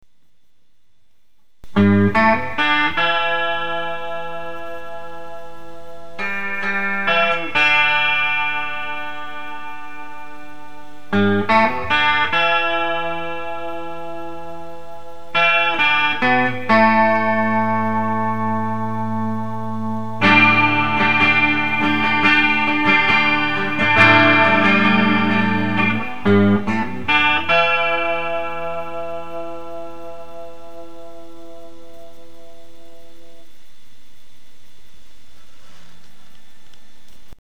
A 12 string made from bits from lots of guitars solid red finish, twin Wilkinson humbuckers
12string.mp3